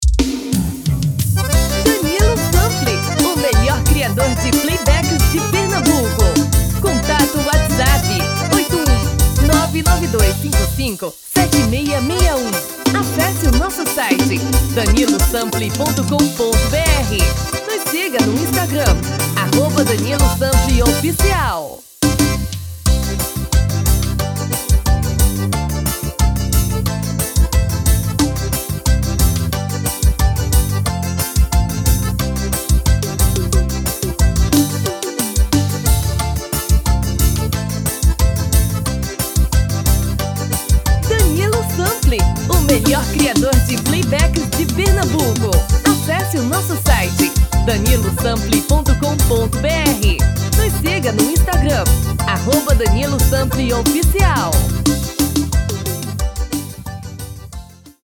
DEMO 1: tom original DEMO 2: Tom feminino